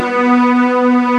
STRINGB.M1C4.wav